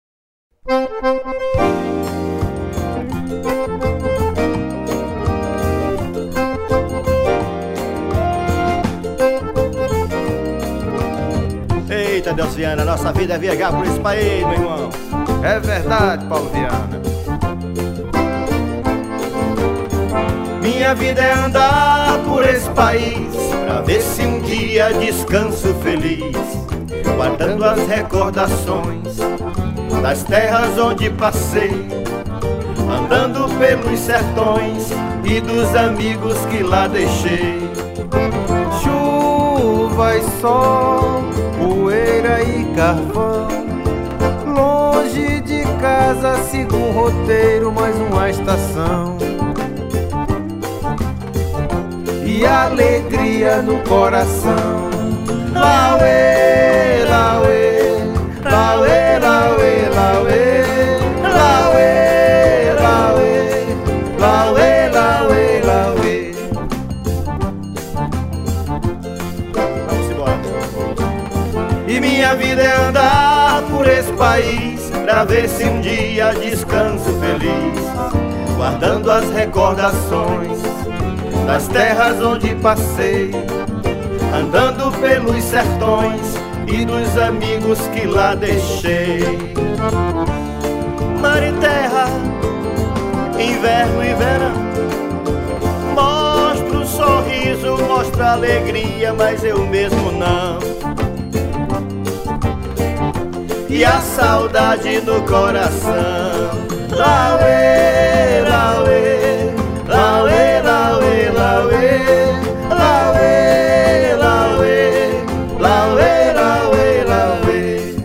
1407   02:25:00   Faixa:     Xote
Acoordeon
Triângulo
Guitarra
Baixo Elétrico 6
Cavaquinho
Bateria
Zabumba, Pandeiro